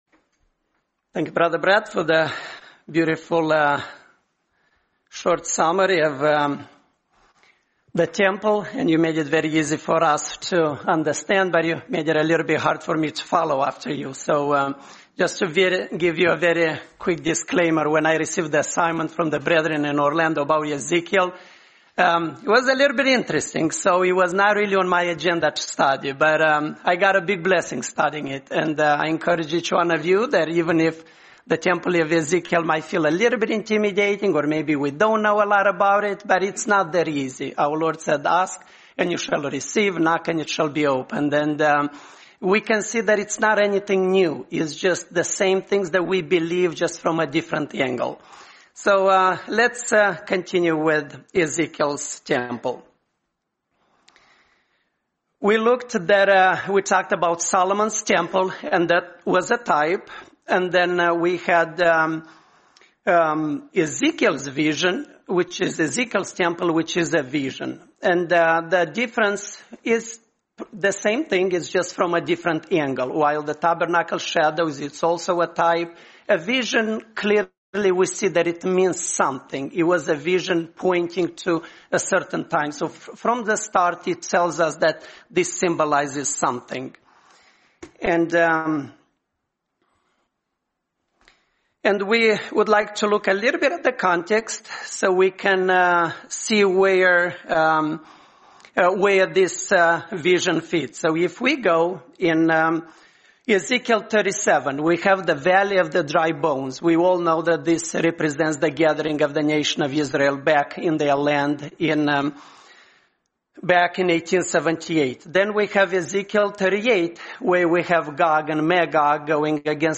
Series: 2025 Florida Convention
Service Type: Presentations